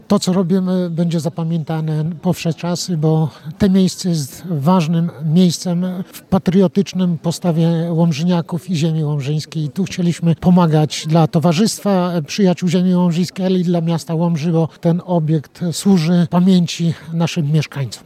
Głównym punktem uroczystości było podniesienie i poświęcenie krzyża na „Mokrej Łączce” – miejscu kaźni bohaterów Powstania.